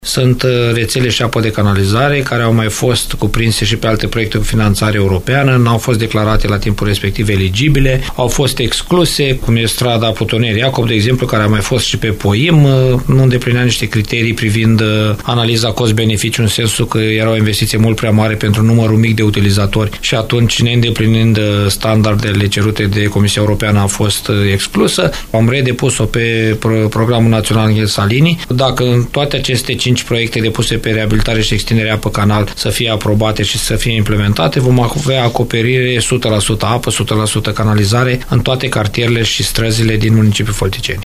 Primarul CĂTĂLIN COMAN a declarat postului nostru că majoritatea proiectelor vizează modernizarea rețelelor de apă și canalizare din municipiu.